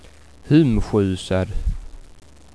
Skånsk ordlista med ljudexempel
kanske därför i stället stavas: "hüm- skjusad" med ett ü-ljud som mycket nära påminner om tyskans korta ü i t ex ordet "fünf". Ordet "hum-skjusad" betyder f.ö. hemskjutsad.